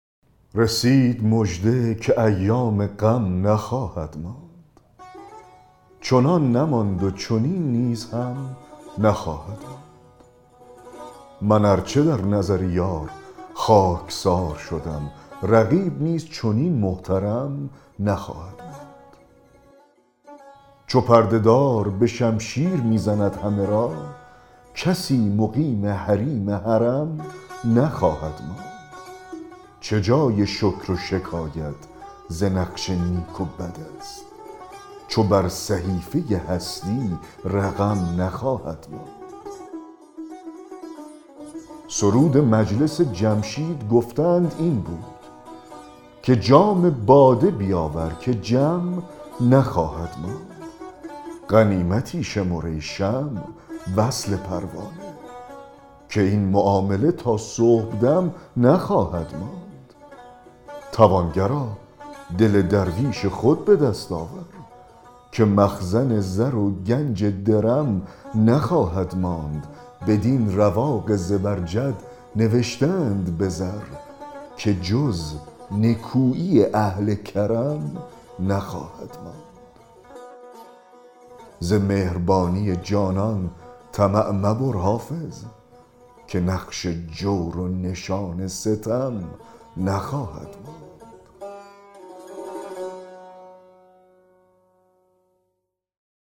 دکلمه غزل 179 حافظ
دکلمه-غزل-179-حافظ-رسید-مژده-که-ایام-غم-نخواهد-ماند.mp3